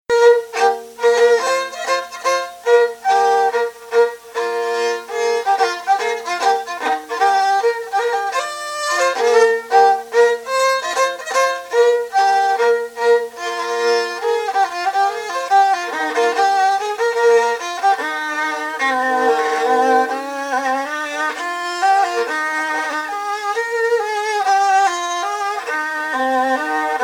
Localisation Montreuil (Plus d'informations sur Wikipedia)
Vendée (Plus d'informations sur Wikipedia)
Fonction d'après l'analyste danse : mazurka-valse ;
Catégorie Pièce musicale inédite